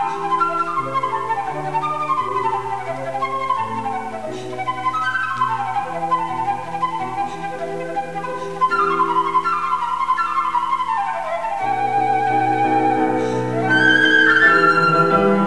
I play it.